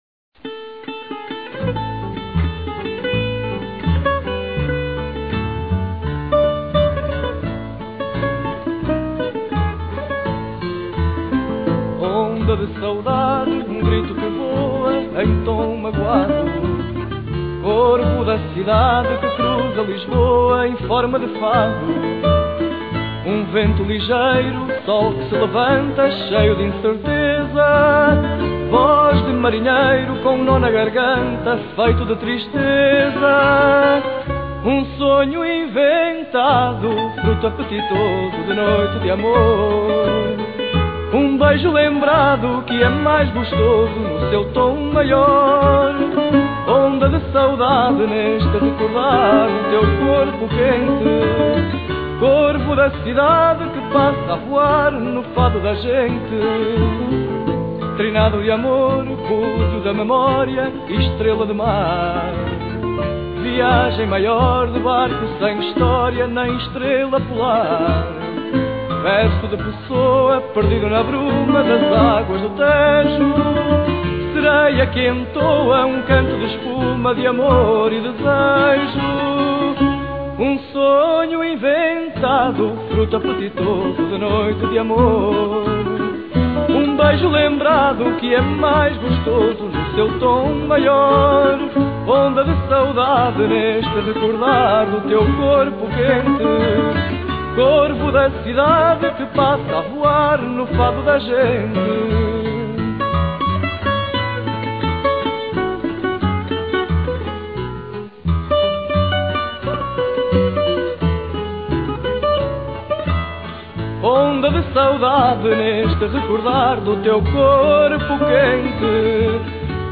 Voz
Guitarra
Viola Baixo